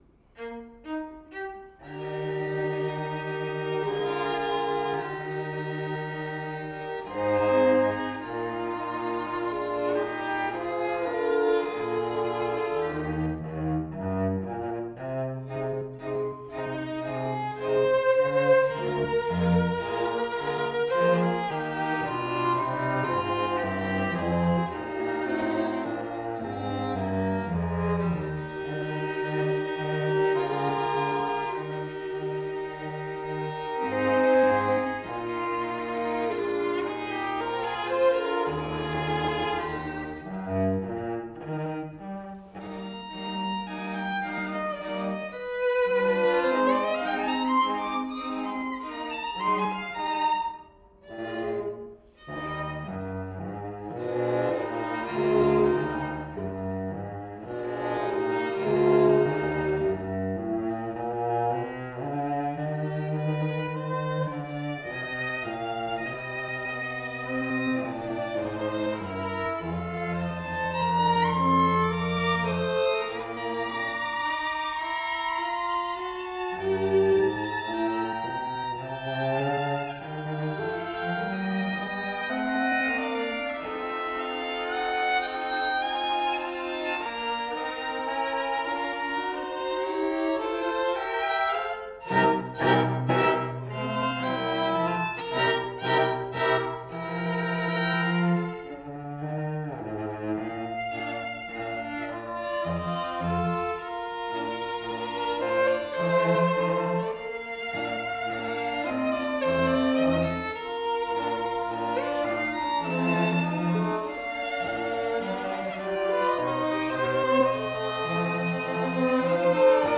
The Andante cantabile features stately themes.